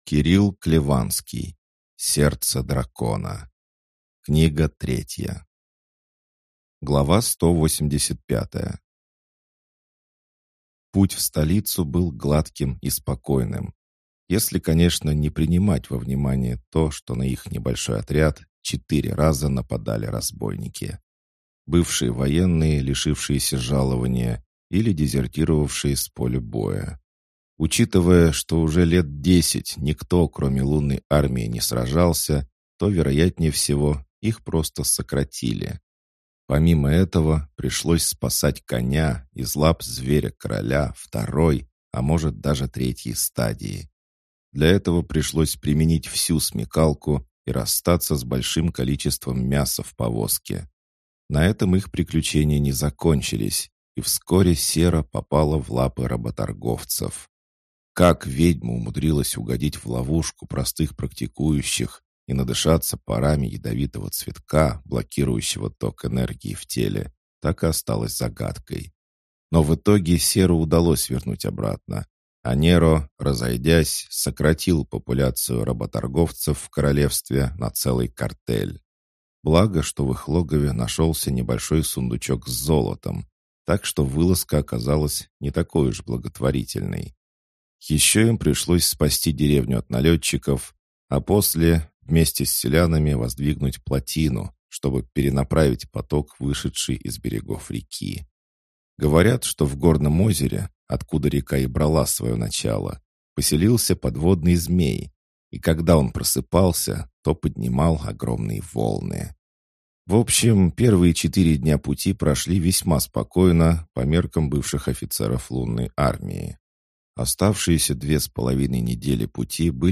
Аудиокнига Сердце Дракона. Книга 3 | Библиотека аудиокниг